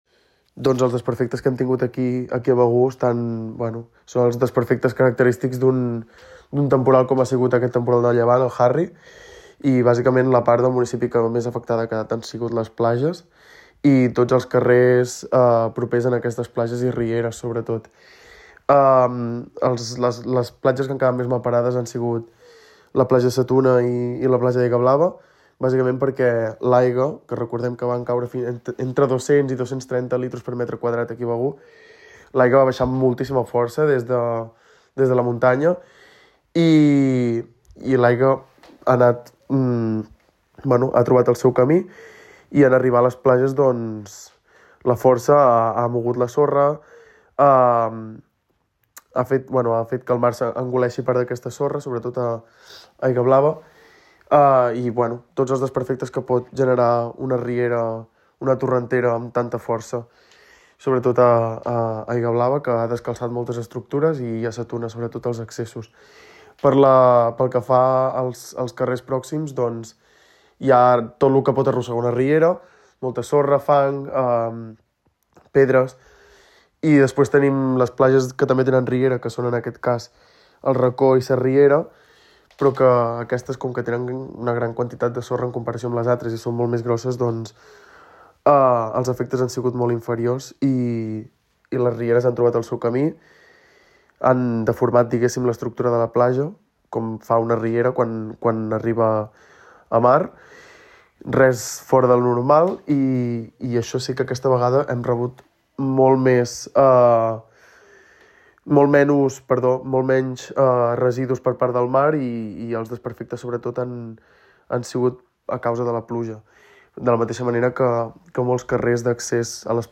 Begur és amb diferència un dels municipis on el temporal Harry ha deixat més desperfectes a les platges. El regidor de medi ambient i platges del municipi, Jordi González, ha explicat que hi ha hagut esllavissades, arrossegament de sorra, caigudes d’arbres i moviments de sorra.